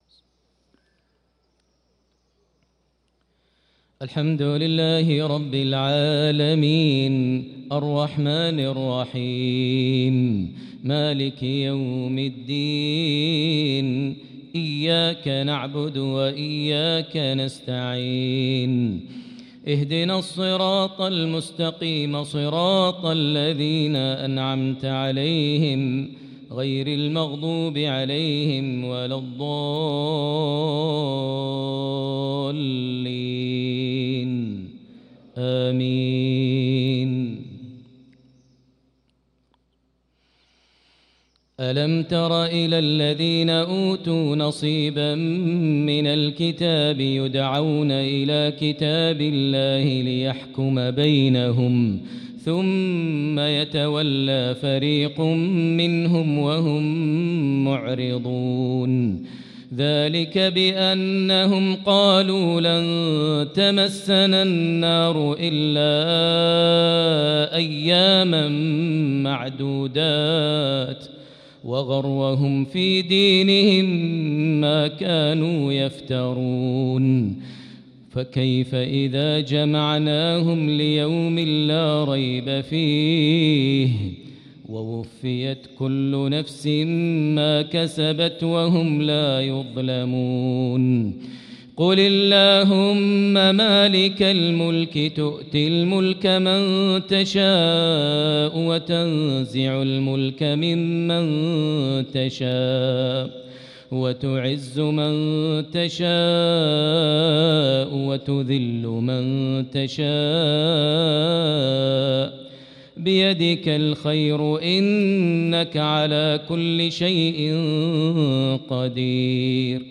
صلاة العشاء للقارئ ماهر المعيقلي 4 شعبان 1445 هـ
تِلَاوَات الْحَرَمَيْن .